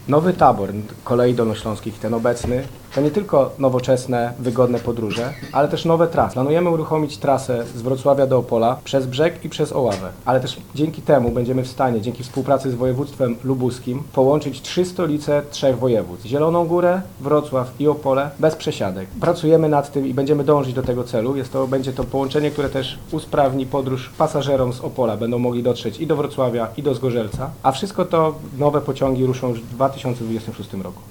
W planach jest także połączenie stolic trzech województw: dolnośląskiego, lubuskiego i opolskiego. O szczegółach mówi Michał Rado, wicemarszałek województwa dolnośląskiego.